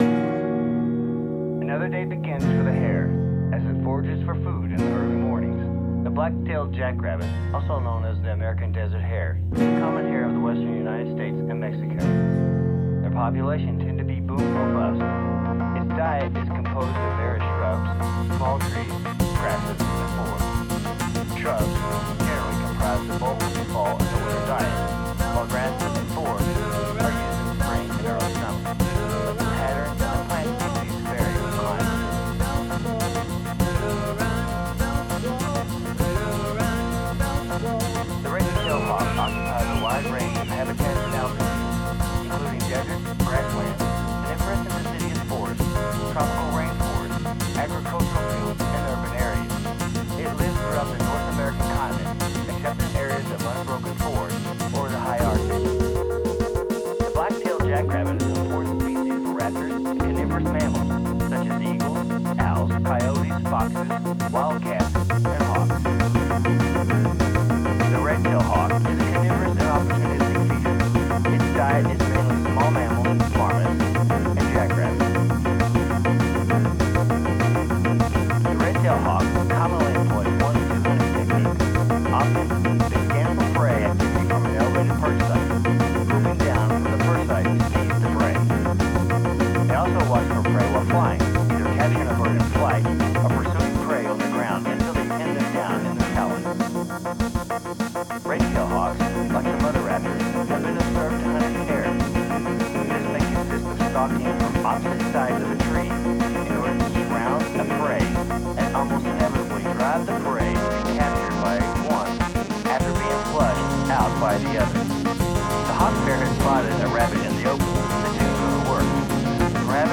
Needs Some Ideas - Need a vocal to sound like it is coming from Radio/TV
I am using the lyrics more as a narration to move the song along. So, music foreground, vocals background. I need the vocals to sound like a TV in the background.